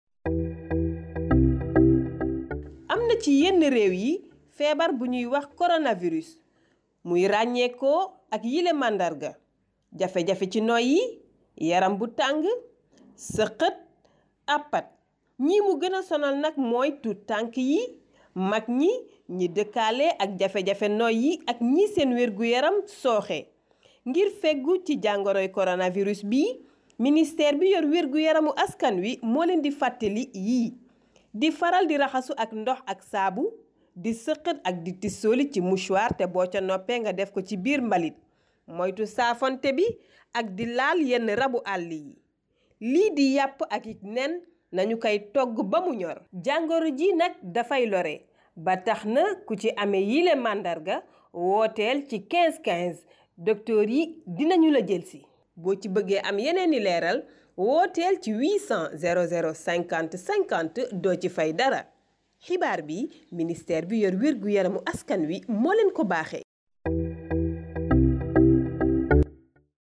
SPOT CORONAVIRUS SIGNE ET PREVENTION
SPOT-Coronaviruscompile-signesetprevention.mp3